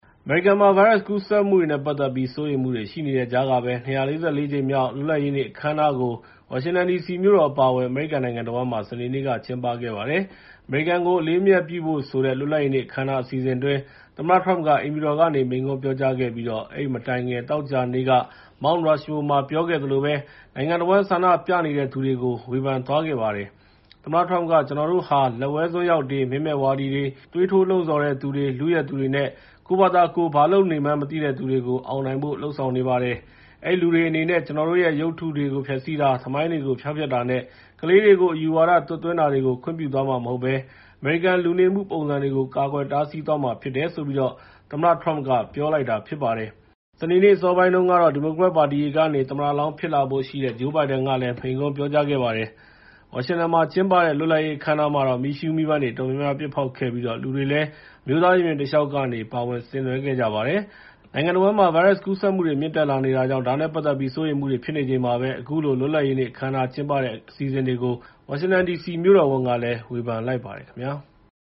အမေရိကန်ကို အလေးအမြတ်ပြုဖို့ဆိုတဲ့ လွတ်လပ်ရေးနေ့ အခမ်းအနား အစီအစဉ်အတွင်း သမ္မတ Trump က အိမ်ဖြူတော်ကနေ မိန့်ခွန်းပြောကြားခဲ့ပြီး အဲဒီမတိုင်ခင် သောကြာနေ့က Mount Rushmore မှာ ပြောခဲ့သလိုမျိုး နိုင်ငံတဝန်း ဆန္ဒပြနေတဲ့သူတွေကို ဝေဖန်လိုက်ပါတယ်။